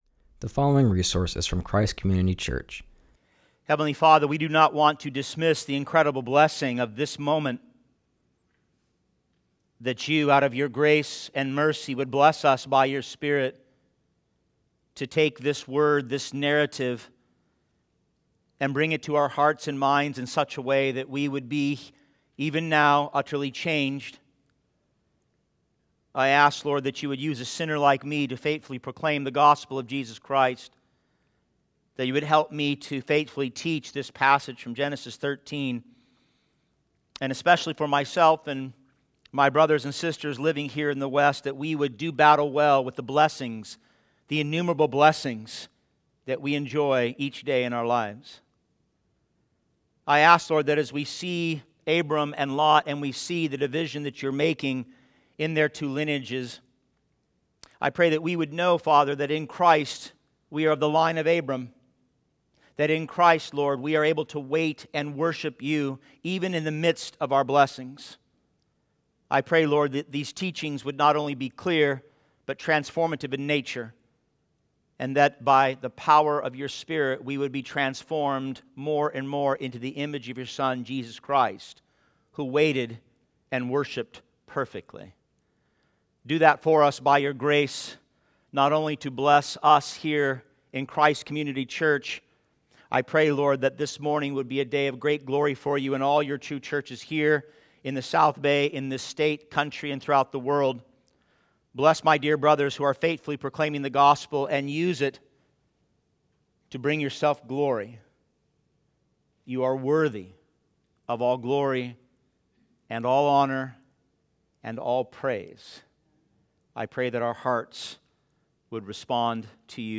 continues our series and preaches from Genesis 13:1-18.